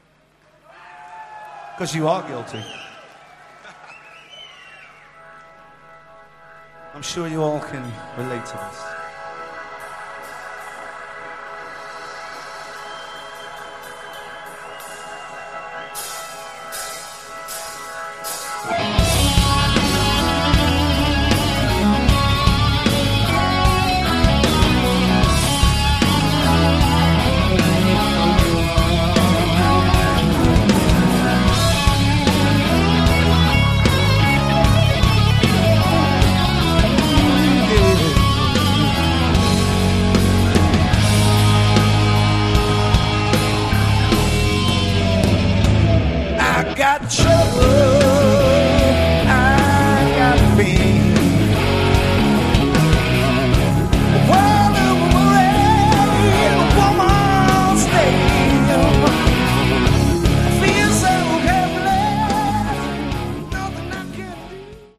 Category: Melodic Hard Rock
Vocals, Guitar
Bass
Keyboards
Drums
Live